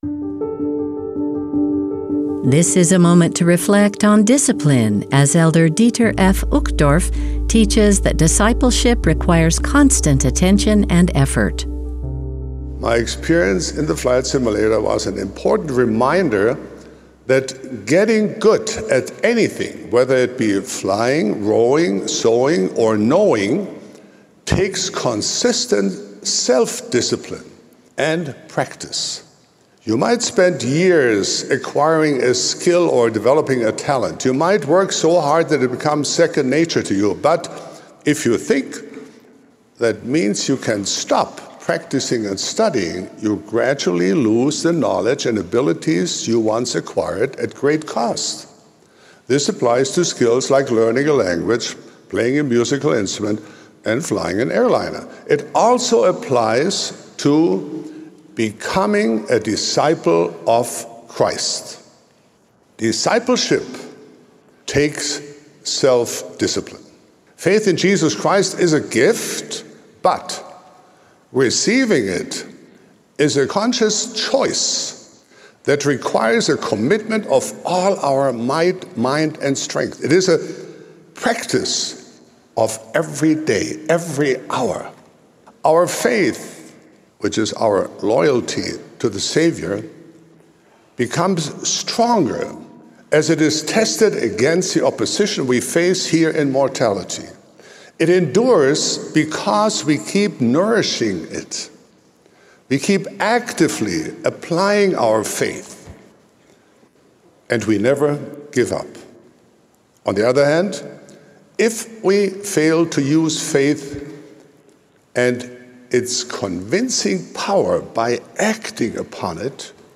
Take "A Moment To Reflect" with us on BYU radio while we share some of the most inspiring and uplifting thoughts from recent addresses.
This is a moment to reflect on discipline, as Elder Dieter F. Uchtdorf teaches that discipleship requires constant attention and effort.